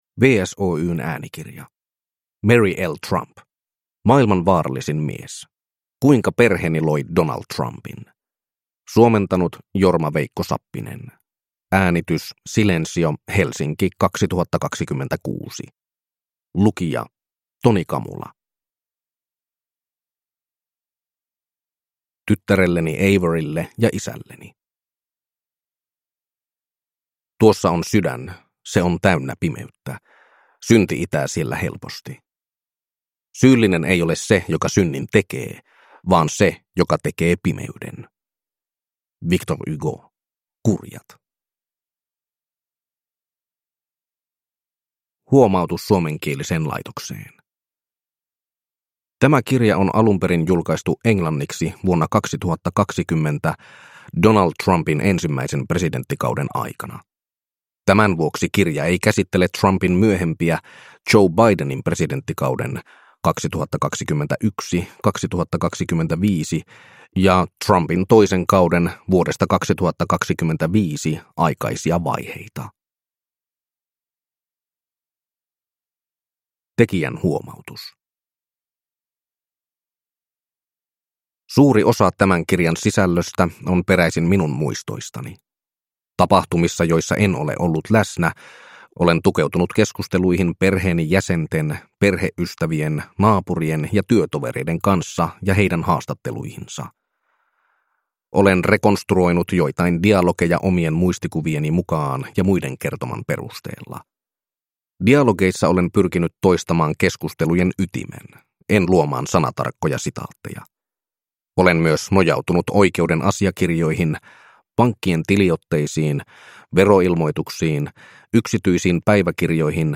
Maailman vaarallisin mies. Kuinka perheeni loi Donald Trumpin – Ljudbok